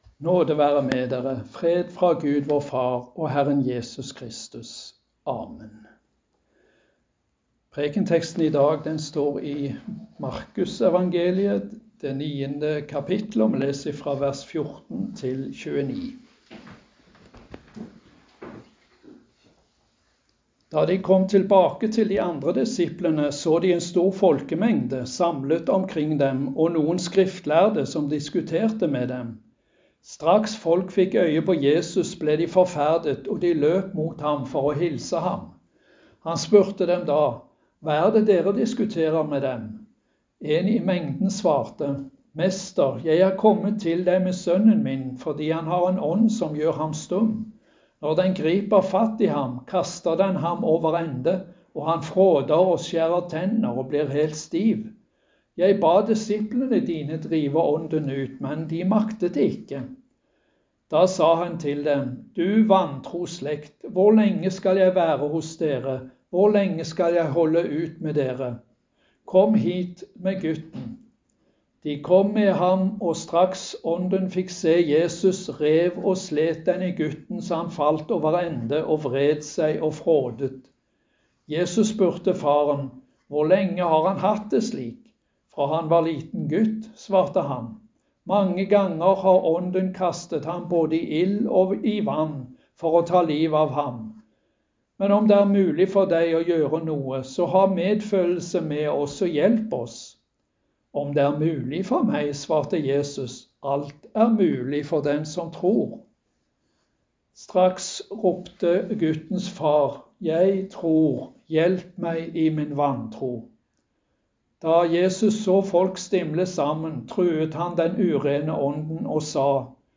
Preken på 2. søndag i faste